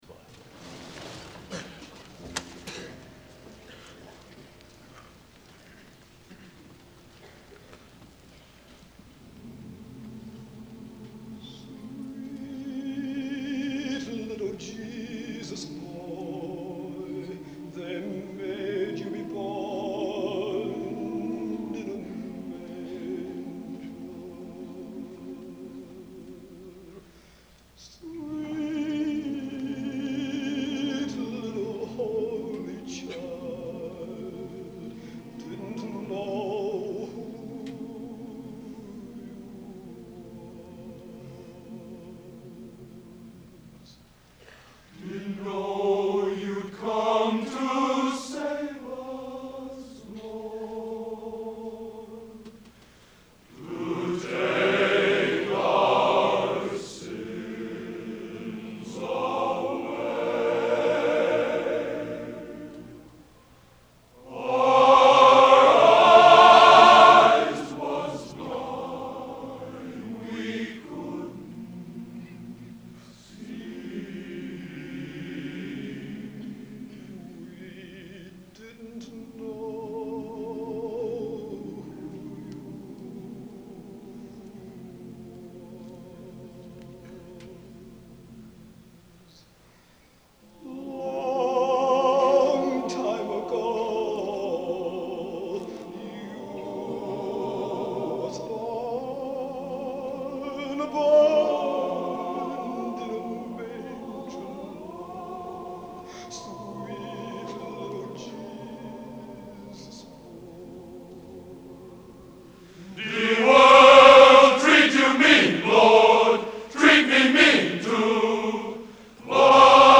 Location: West Lafayette, Indiana
Genre: Spiritual | Type: